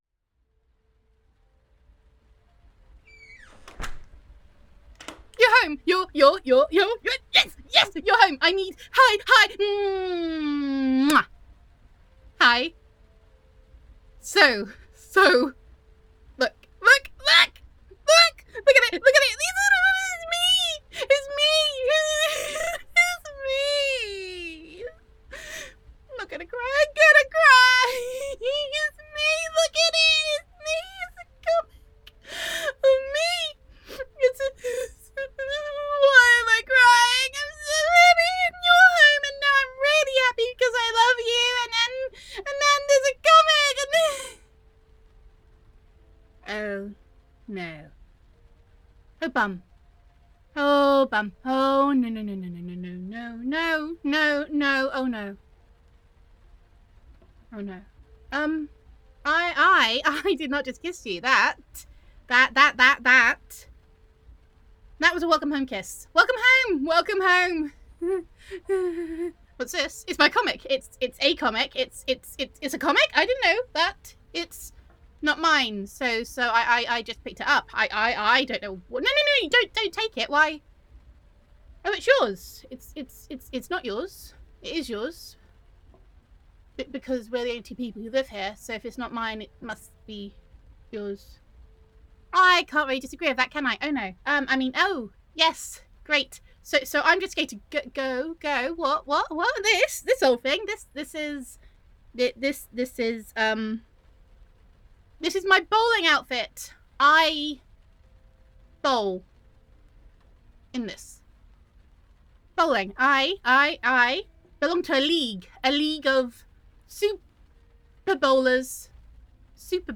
[F4A] What a Coincidence [Flatmate Roleplay]
[Flustered]